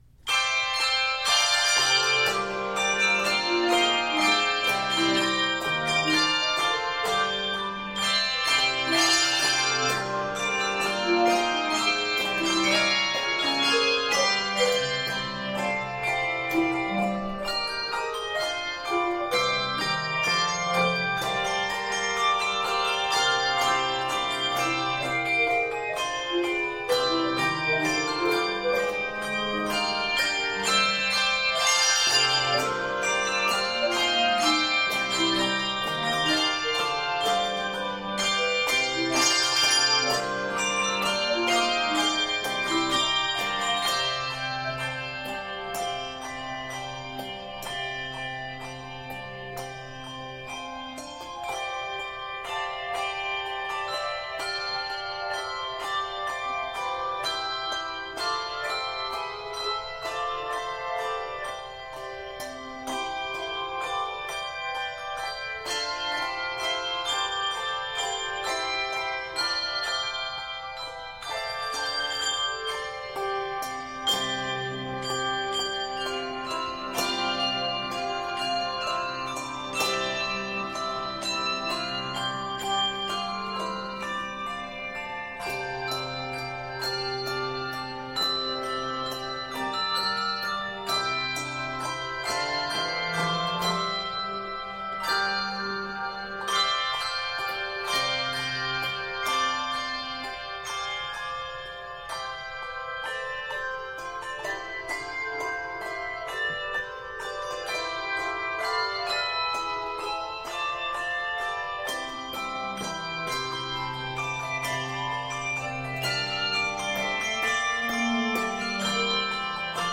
triumphant and majestic